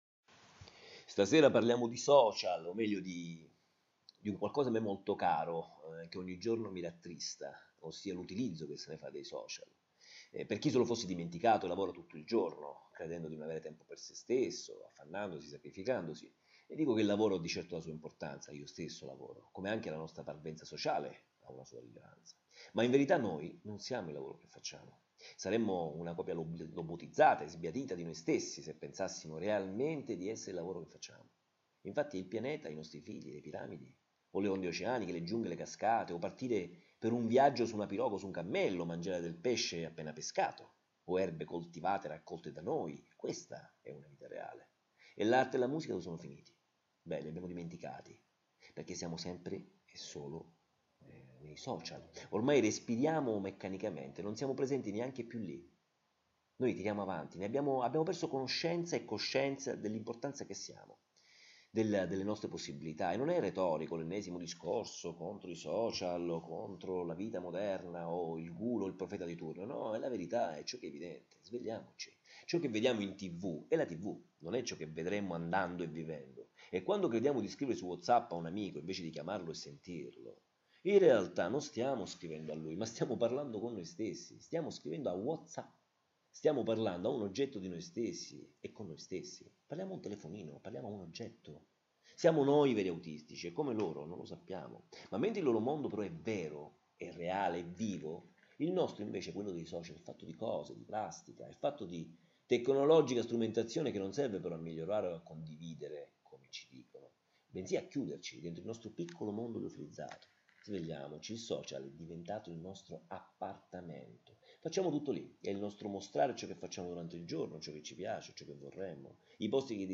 I minuti dei file audio riproducono le riflessioni degli articoli a voce alta, perché abbiano accesso all’ascolto i ciechi e quelli tra noi che pur avendo la vista sono diventati non vedenti,